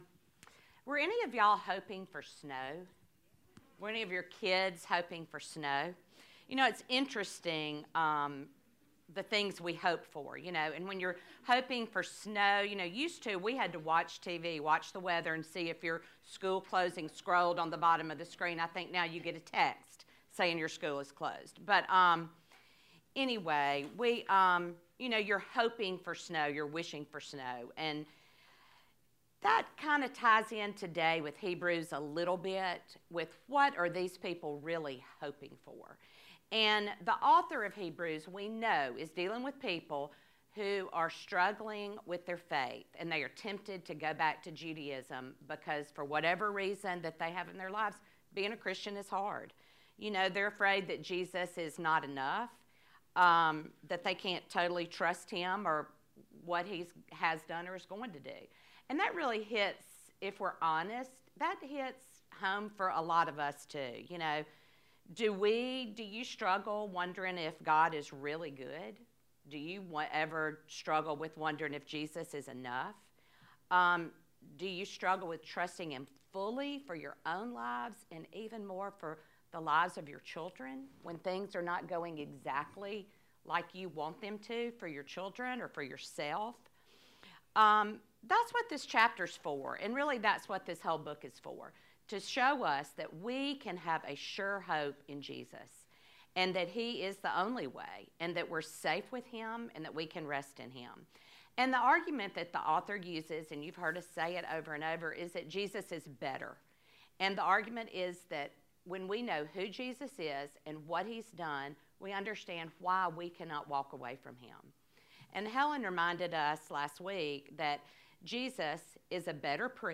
Hebrews Lesson 12